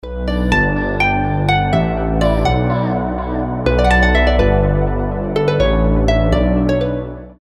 спокойные
без слов
красивая мелодия
звонкие
Приятная мелодия на уведомления и сообщения